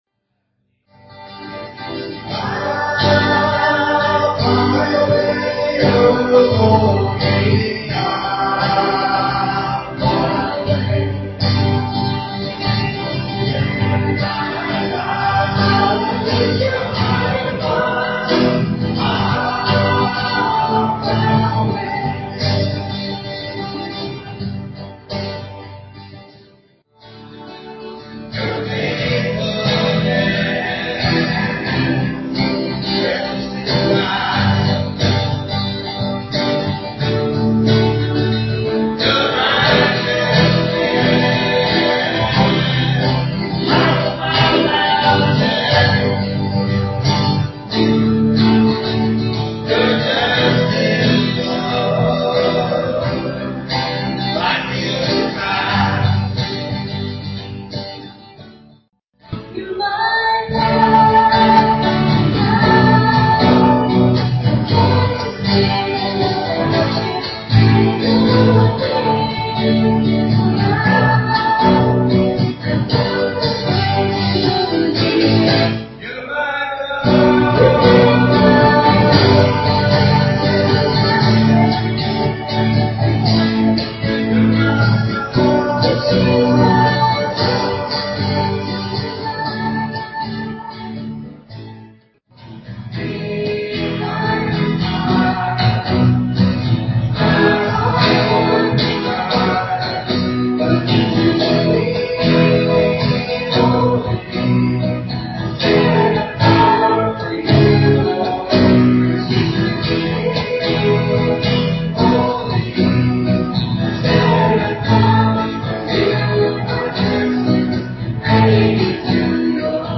PLAY 51st Anniversary Music & Message , March 20, 2011 Scripture: John 9:1-7.
EBBC Worship Band
guitar and vocals
keyboard and vocals
electric bass, drums, vocals.